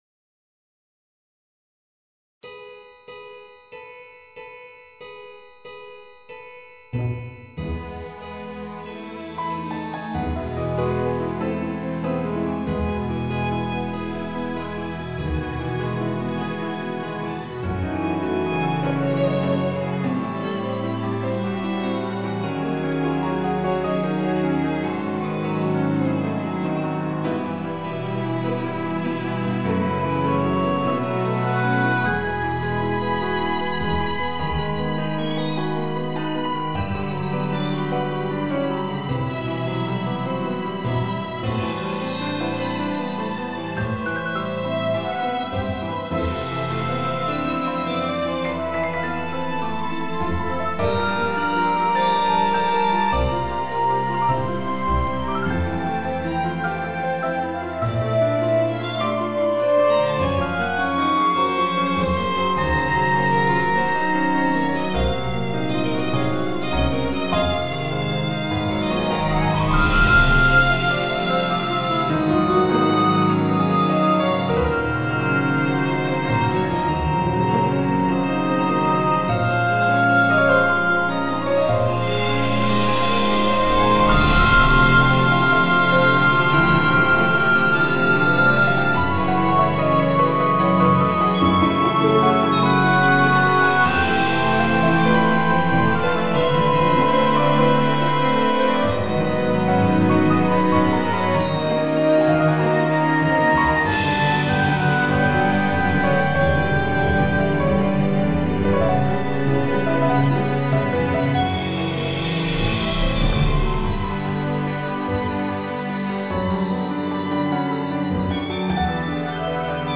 Le clavecin colle parfaitement au thème, bien vu !
Techniquement, je n'ai rien à dire, tout s'entend à merveille, beaucoup de finesse et de légereté !
Well the dance of my puppets is a slow rhytmic dance in 106 for a quarter note on the harpsichord.
Note: I hated the violin sounding too much panned to the right.
Very nice music, quite Jazzy and easy to listen, I think just that you could mix your music a little bit more cause sometimes some instruments are too loud and disturb the whole thing